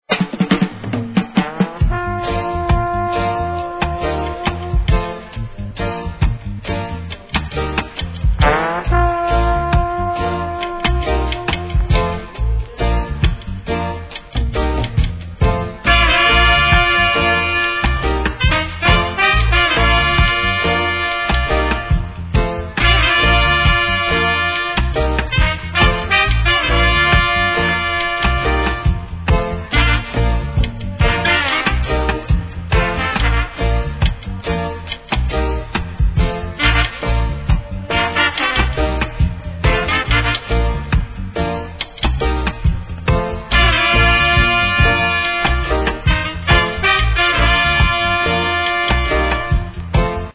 REGGAE
'70sホーン・インスト!